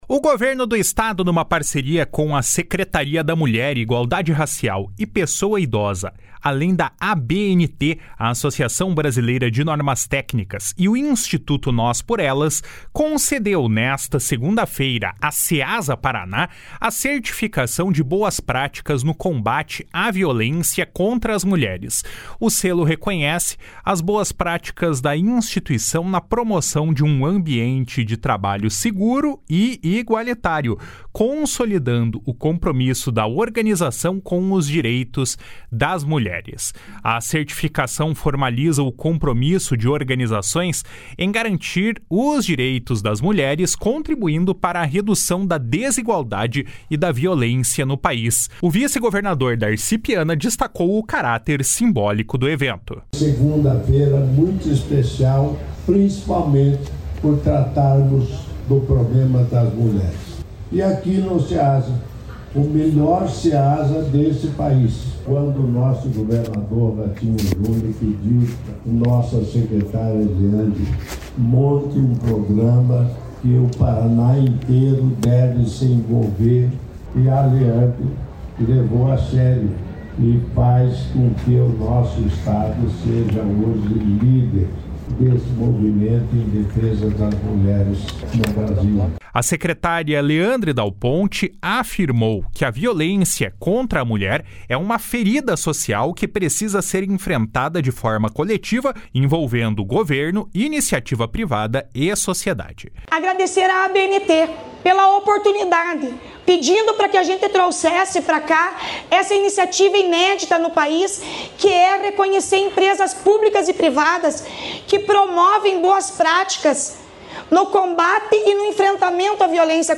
O vice-governador Darci Piana destacou o caráter simbólico do evento. // SONORA DARCI PIANA //
A secretária Leandre Dal Ponte afirmou que a violência contra a mulher é uma ferida social que precisa ser enfrentada de forma coletiva, envolvendo governo, iniciativa privada e sociedade.// SONORA LEANDRE DAL PONTE //